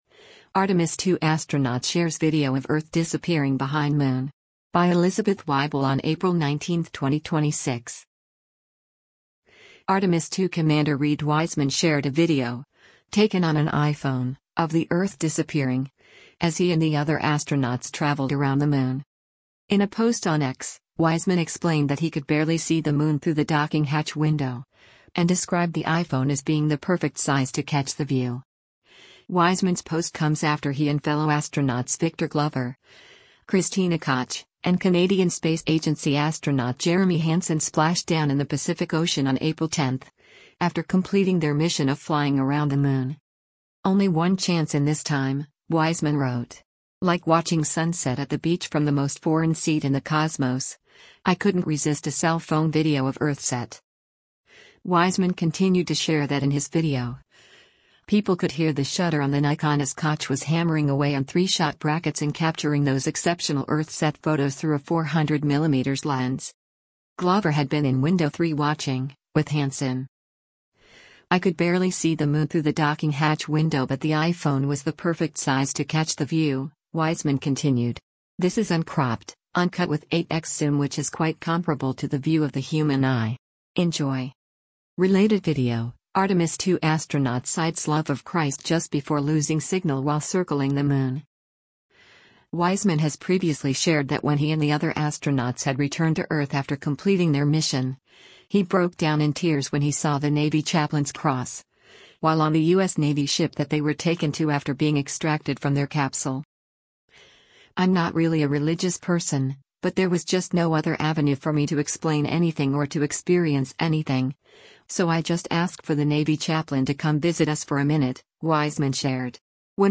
Artemis II Commander Reid Wiseman shared a video, taken on an iPhone, of the Earth disappearing, as he and the other astronauts traveled around the Moon.
Wiseman continued to share that in his video, people could “hear the shutter on the Nikon” as Koch was “hammering away on 3-shot brackets and capturing those exceptional Earthset photos” through a 400mm lens.